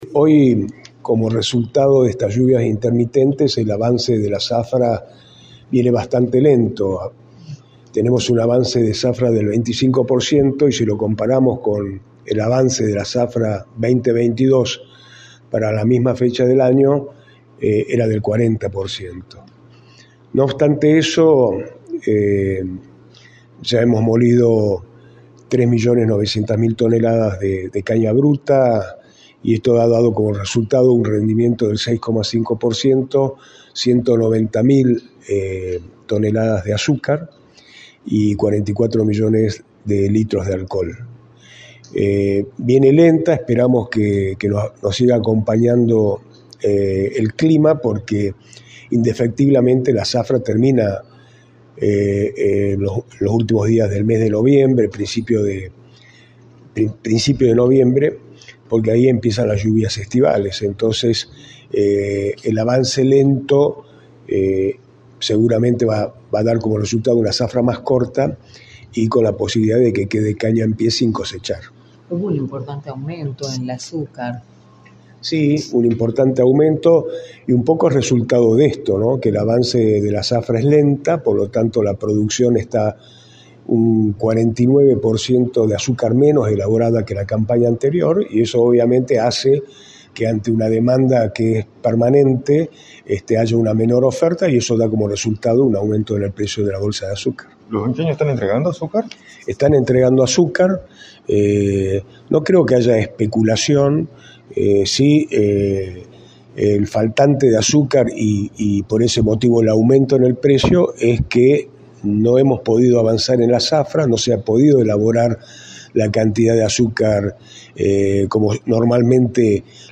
Álvaro Simón Padrós, Ministro de Producción, informó en Radio del Plata Tucumán, por la 93.9,  los efectos que tuvo el aumento del precio de la bolsa de azúcar, los motivos de la misma y como se encuentra la situación productiva de la zafra en Tucumán.
“Hubo un importante aumento en el precio producto de que el avance de la producción es lento, por lo tanto la producción de azúcar está un 49% menos que la campaña anterior, los Ingenios si están entregando azúcar, no creo que haya especulación” señaló el Ministro Álvaro Simón Padrós en entrevista para «La Mañana del Plata» por la 93.9.